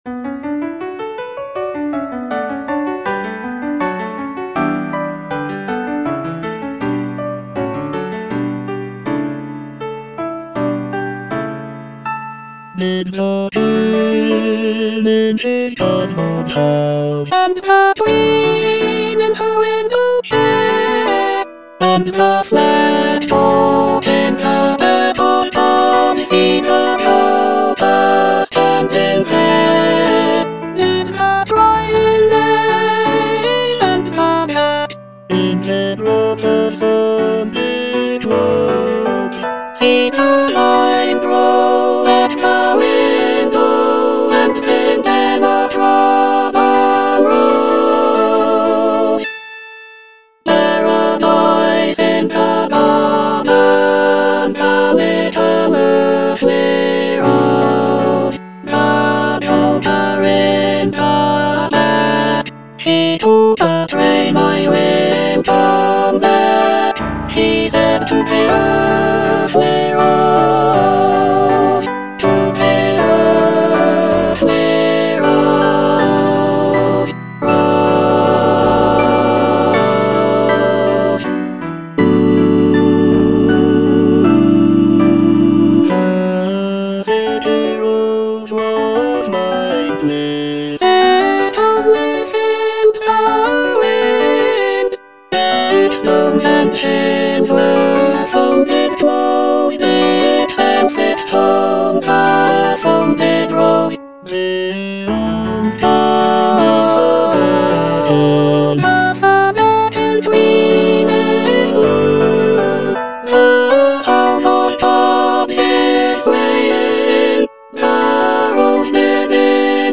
Alto (Emphasised voice and other voices)
is a choral work
With its lush textures and haunting melodies